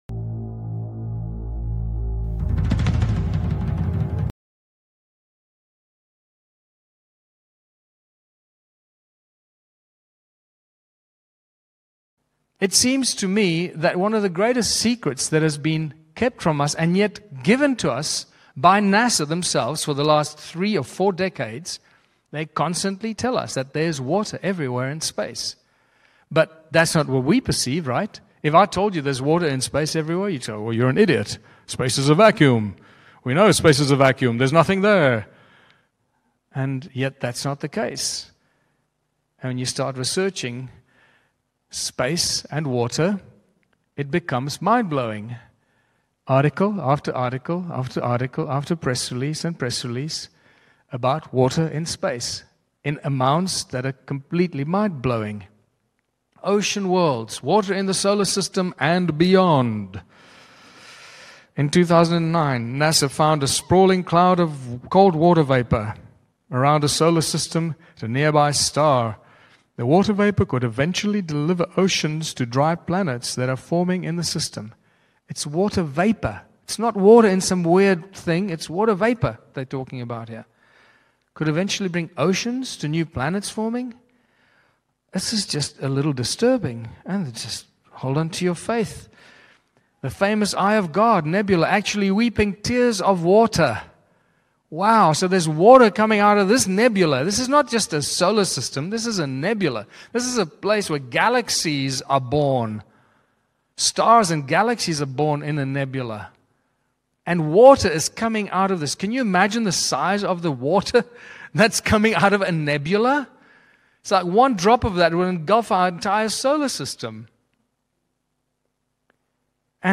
מכירת חיסול של האמת, כמו תמיד, כדי שנמשיך להיות עבדים זהו חלק שני מהרצאה בת כשעתיים.